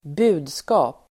Uttal: [²b'u:dska:p]